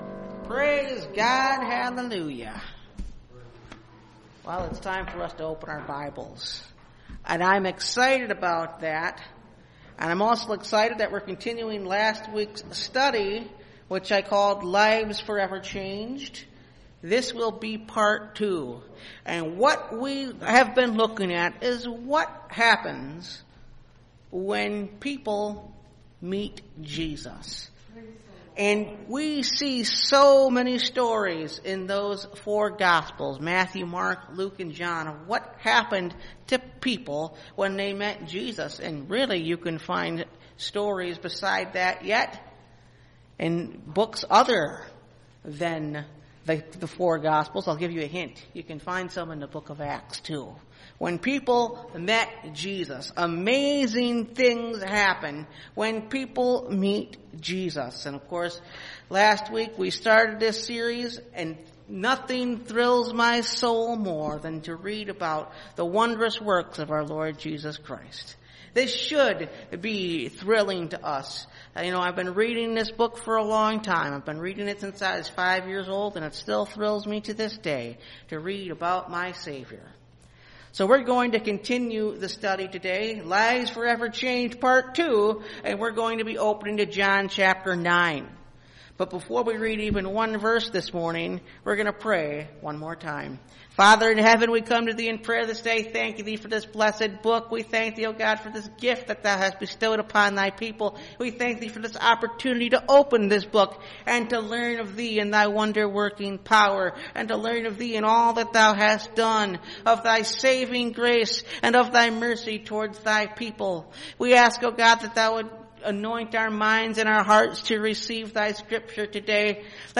Lives Forever Changed Part 2 (Message Audio) – Last Trumpet Ministries – Truth Tabernacle – Sermon Library